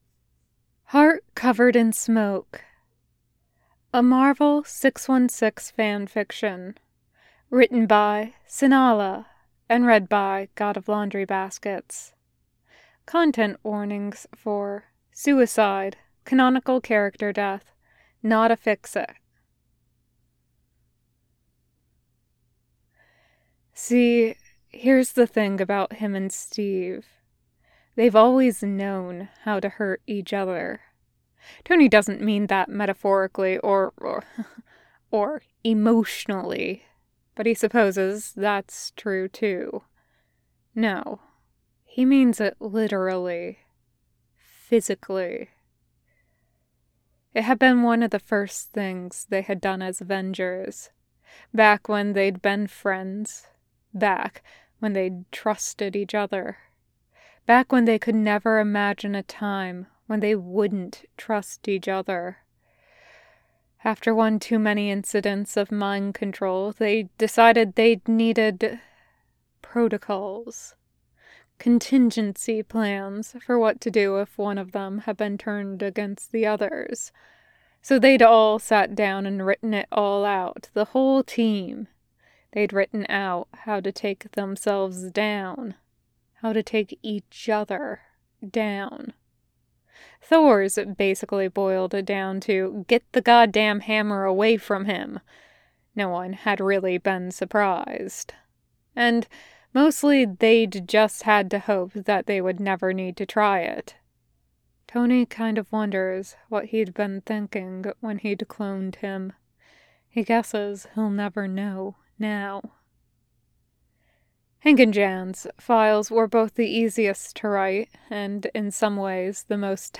[Podfic] Heart Covered in Smoke